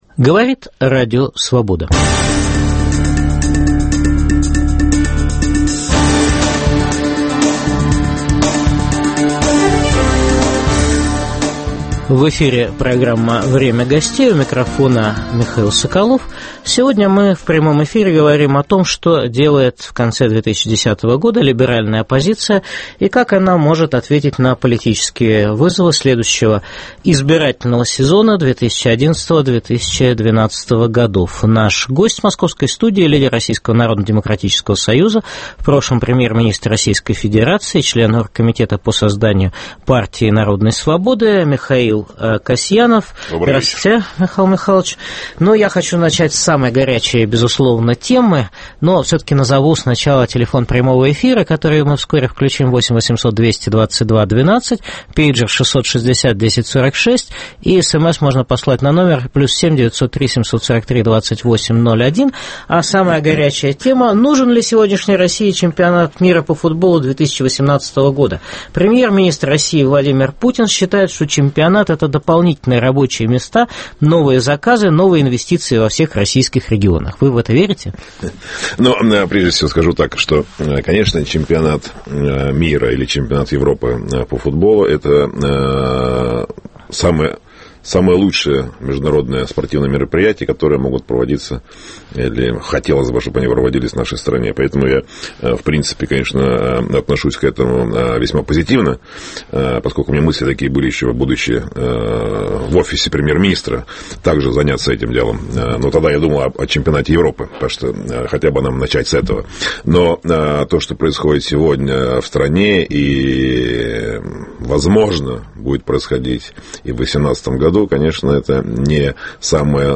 Либералы и вызов 2011-2012 года. В программе выступит член оргкомитета Партии народной свободы, лидер Российского народно-демократического союза Михаил Касьянов.